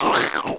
Index of /App/sound/monster/skeleton_soldier_spear
fall_1.wav